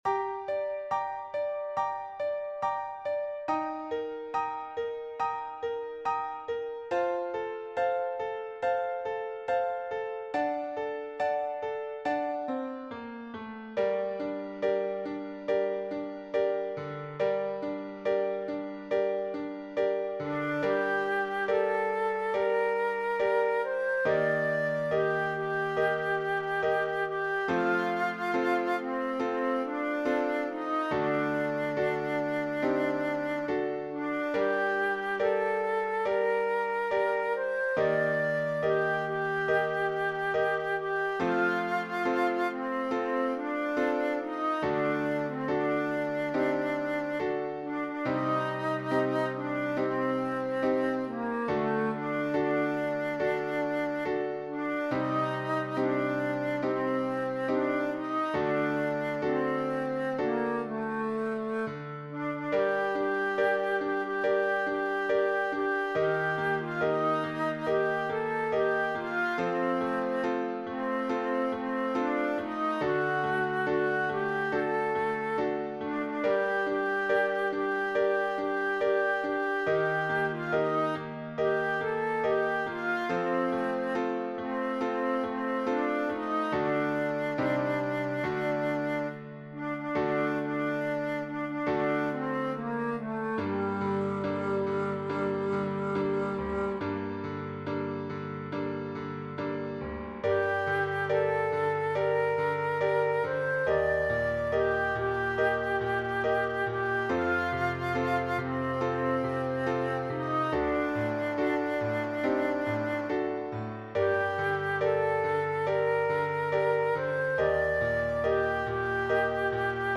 Vocal Solo
Medium Voice/Low Voice